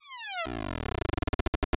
speedDown.wav